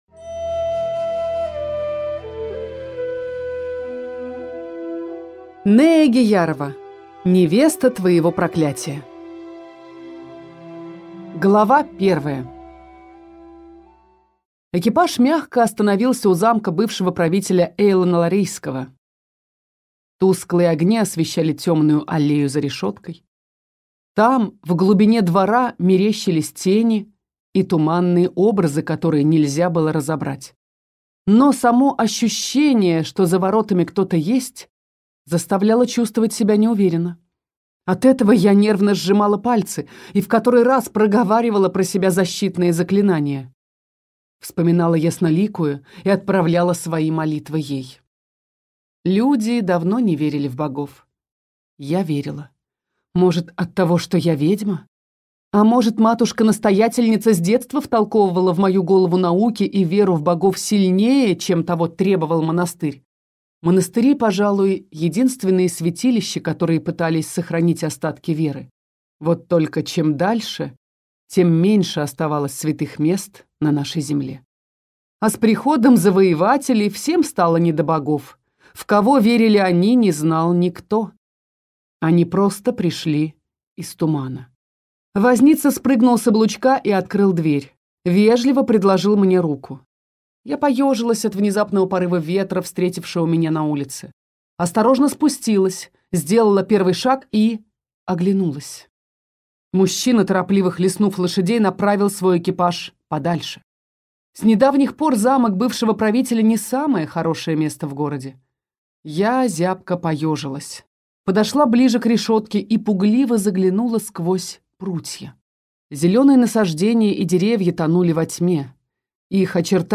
Аудиокнига Невеста твоего проклятия | Библиотека аудиокниг